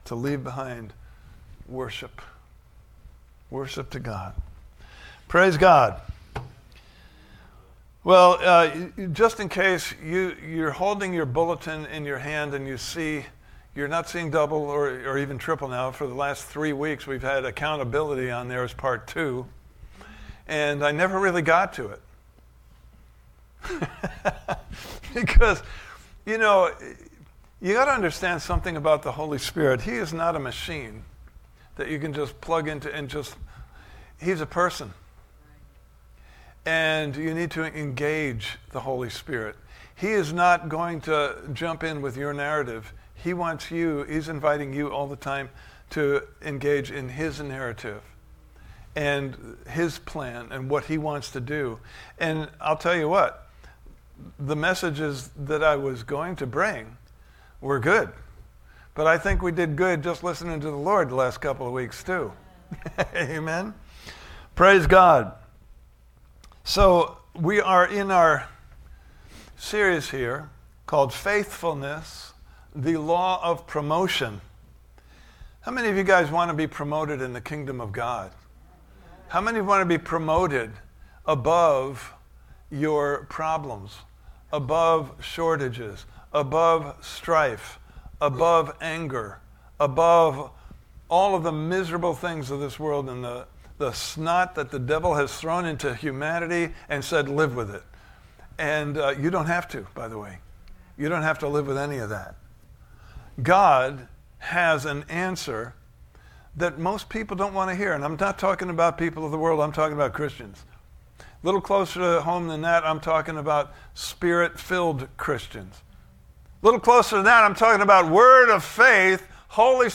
Series: Faithfulness: The Law of Promotion Service Type: Sunday Morning Service